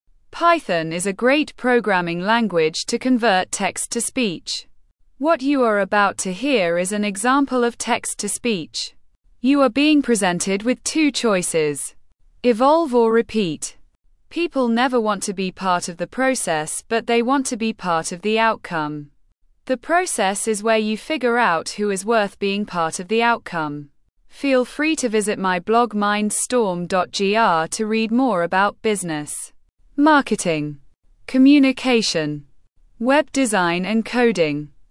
Text to Speech
Female voice – File created (mp3): welcome_3.mp3